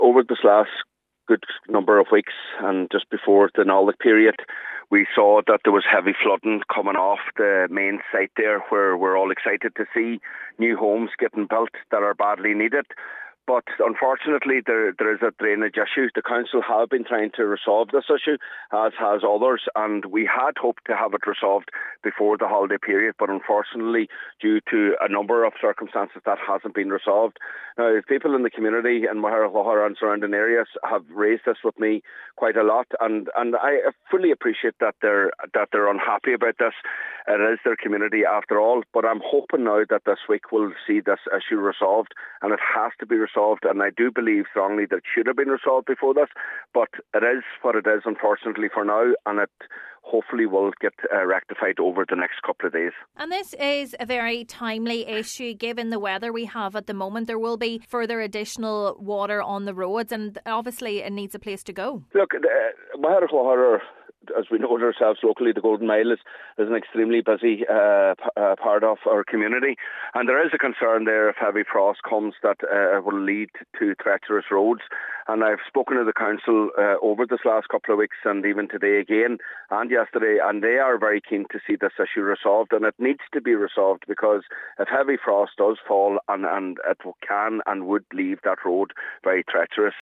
Councillor Micheal Choilm MacGiolla Easbuig says it is an extremely timely manner given the current weather conditions: